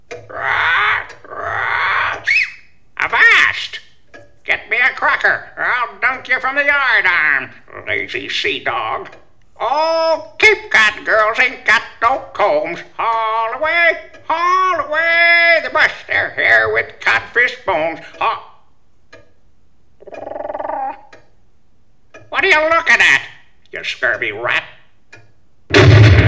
In the attached file, there is a background track of a ticking clock.
The other is another pirate sound effect that works fine on the MP3 board.